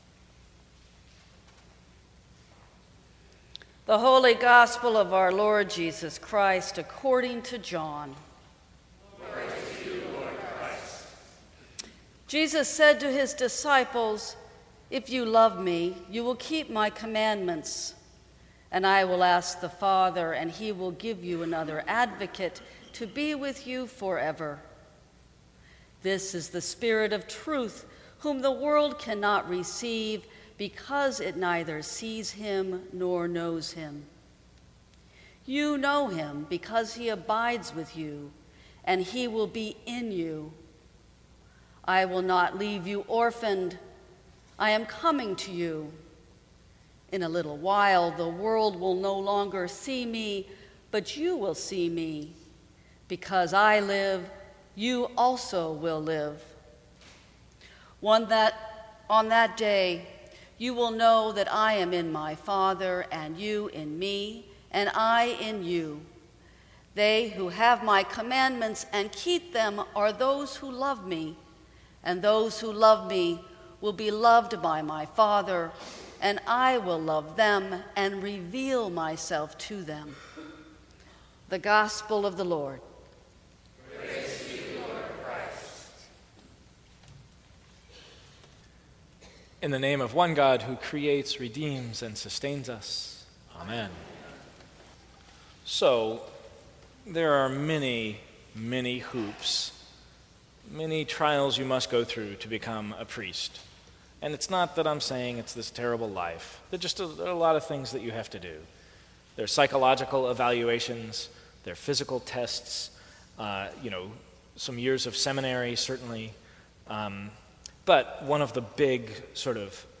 Sermons from St. Cross Episcopal Church A Good Goodbye May 21 2017 | 00:15:38 1x 00:00 / 00:15:38 Subscribe Share Apple Podcasts Spotify Overcast RSS Feed Share Link Embed